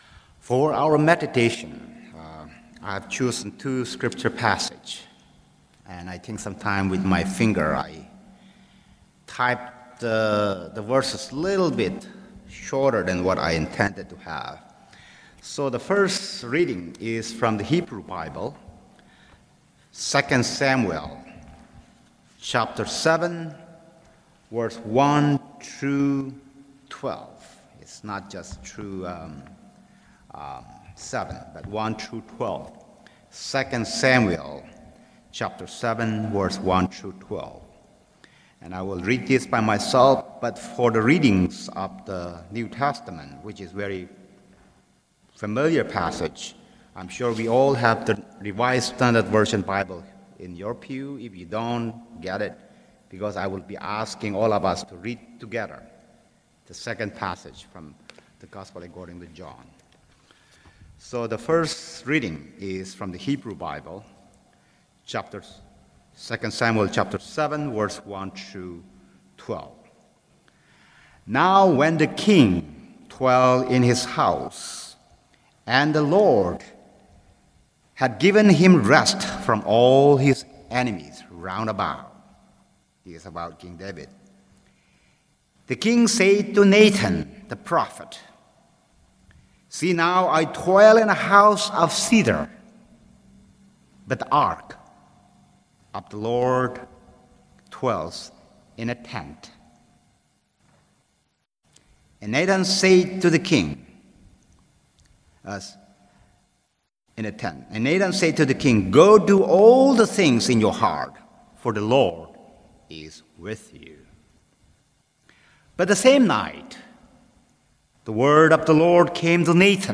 Sermon: Worshipping Beyond Four Walls of the Church | First Baptist Church, Malden, Massachusetts
Sunday Worship Service